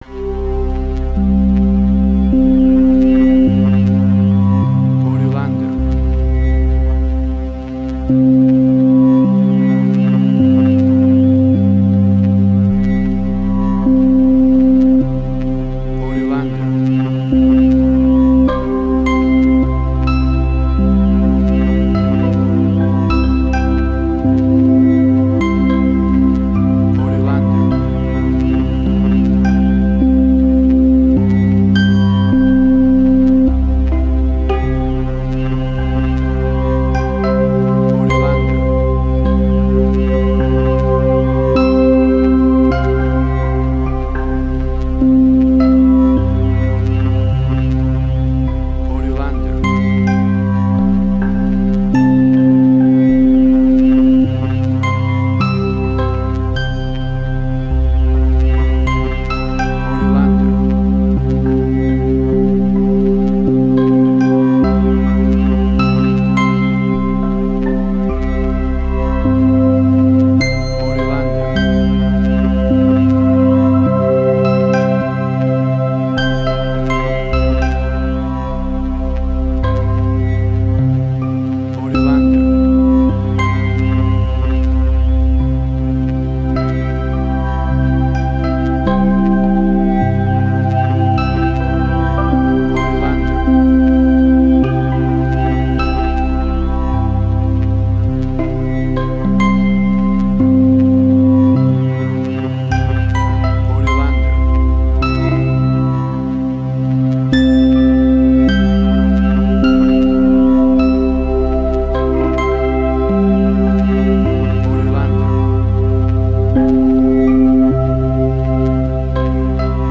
New Age.
emotional music